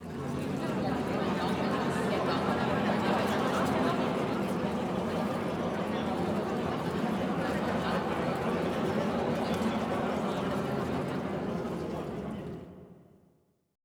Index of /90_sSampleCDs/Best Service - Extended Classical Choir/Partition I/VOICE ATMOS